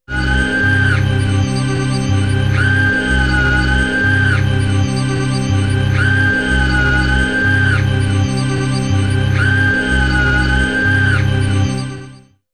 Melody loop 1.wav